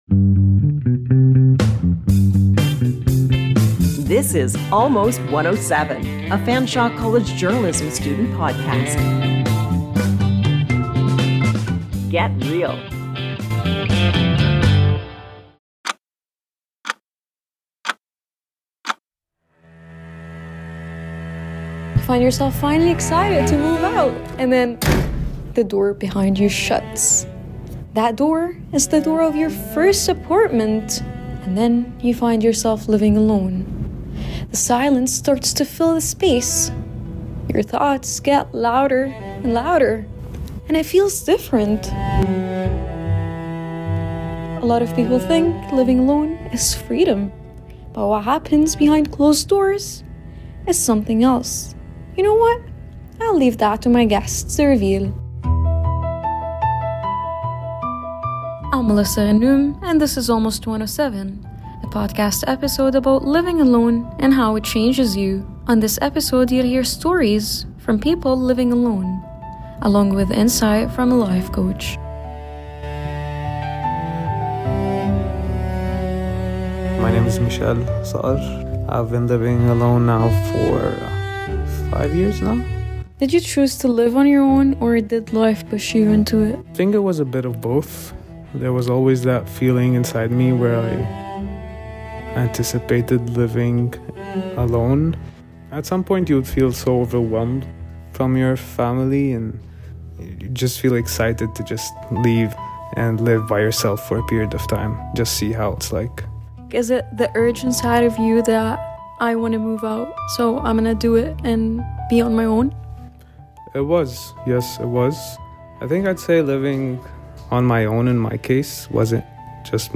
Through honest stories from young adults experiencing independence